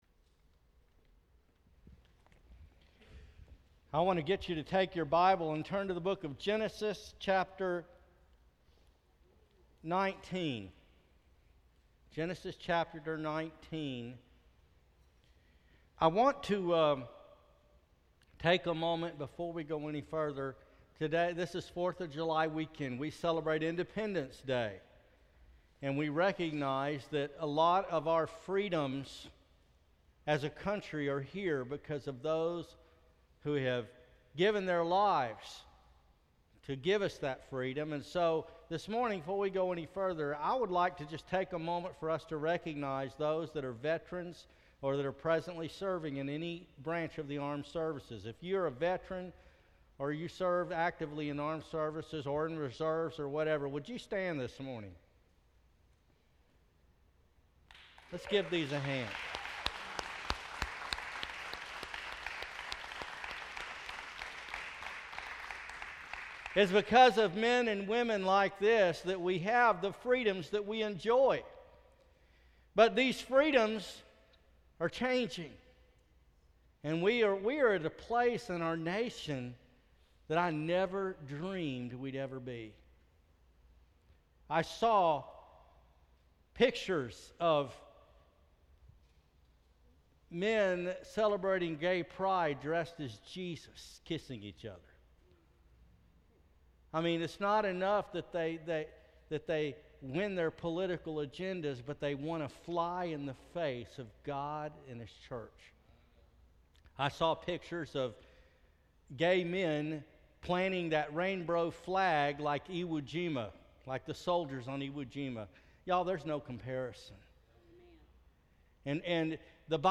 July 5, 2015 Longing for the World Passage: Genesis 19 Service Type: Sunday Morning Worship Bible Text: Genesis 19 Lot was a man faithful to God, but who had too much longing for the world.